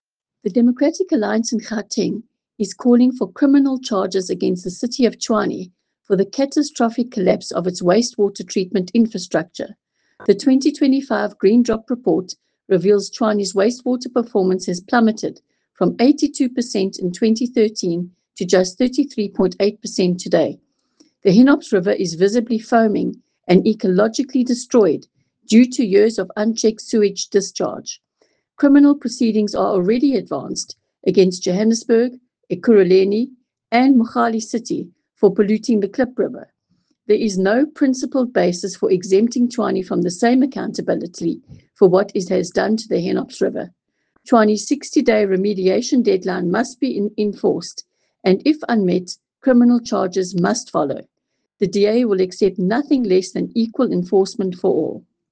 Issued by Leanne De Jager MPL – DA Gauteng Spokesperson for Environment
Note to Editors: Attached please find a soundbite in English